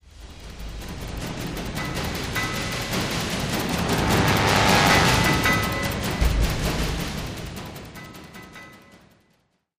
Music Transition; Intense Action Drums Fade In And Out.